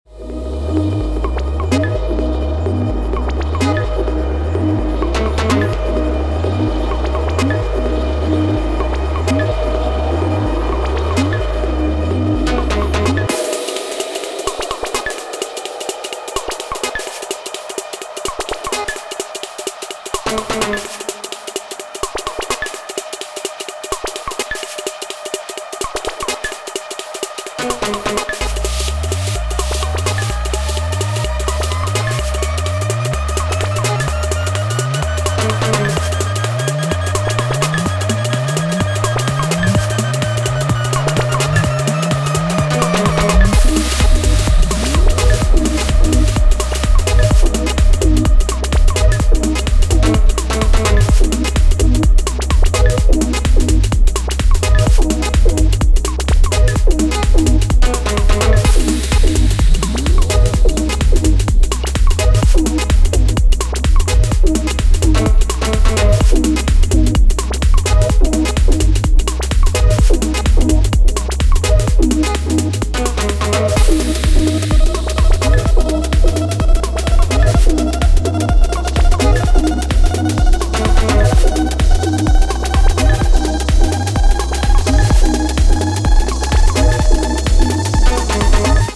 Genres Minimal